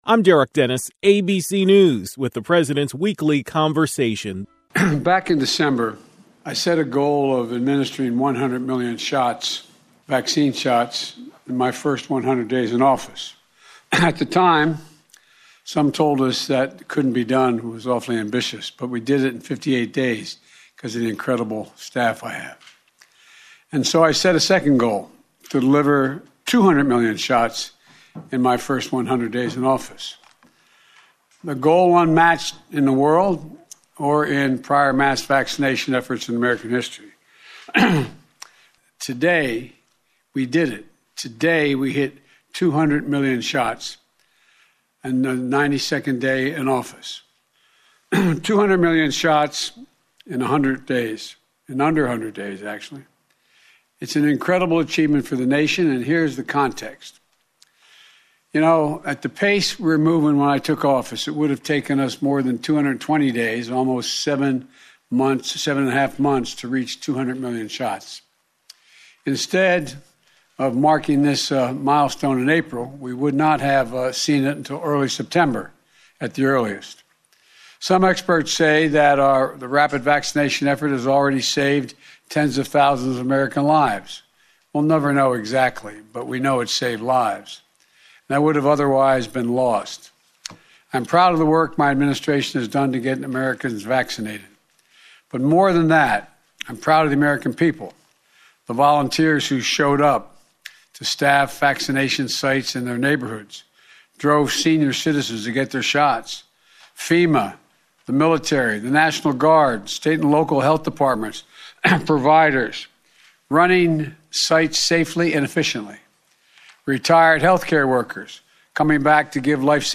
President Joe Biden encourages everyone 16 years of age and over to get vaccinated.